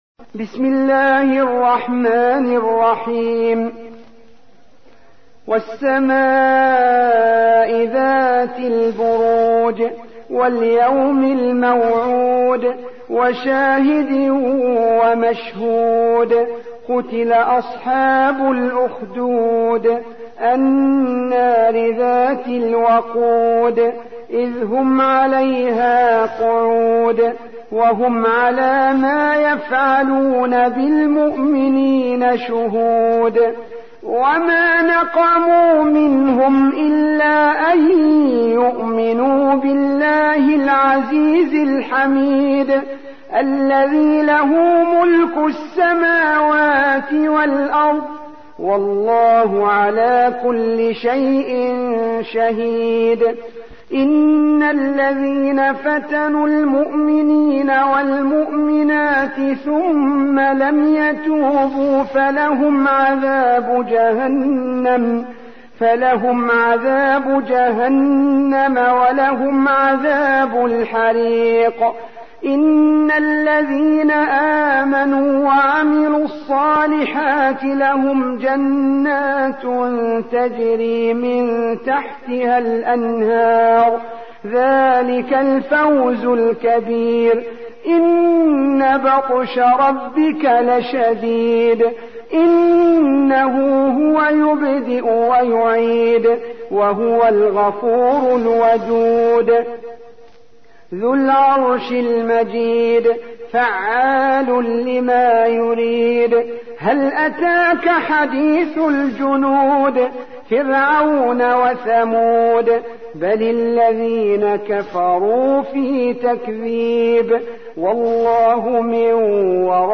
85. سورة البروج / القارئ